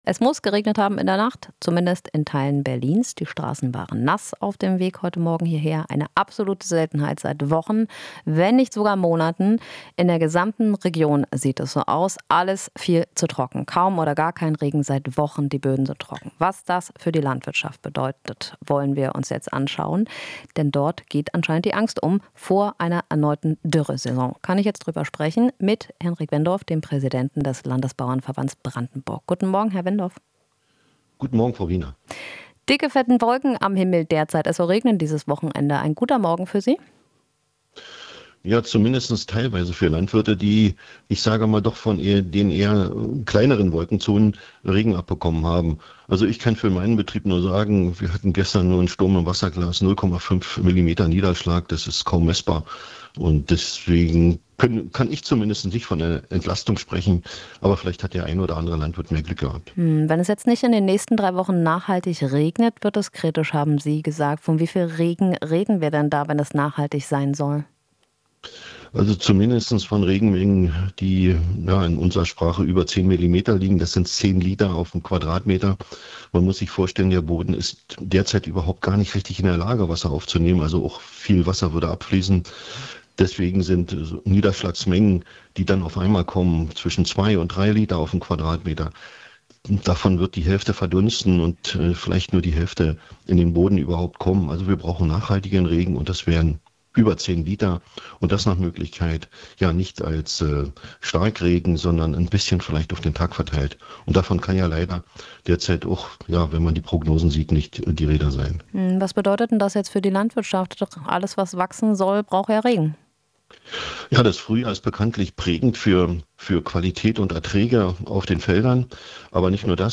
Interview - Landesbauernverband Brandenburg: "Brauchen nachhaltigen Regen"